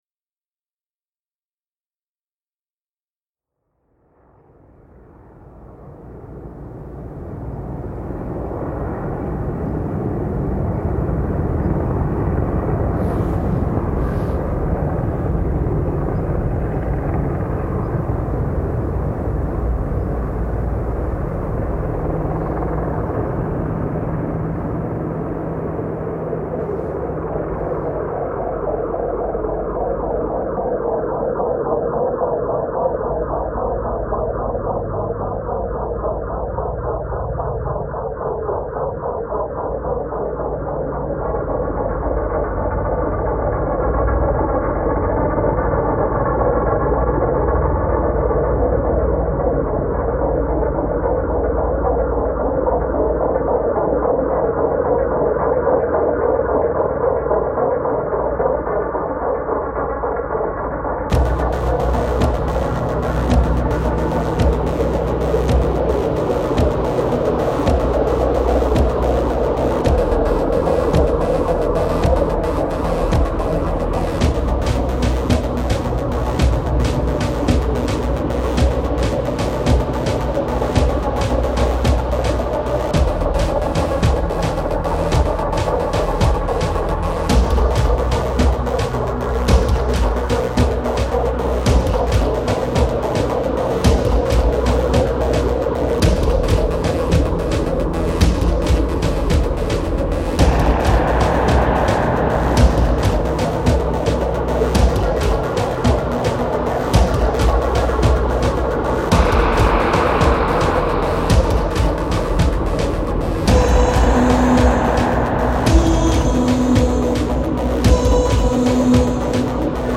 AH-64 Apache flypast in Italian Dolomites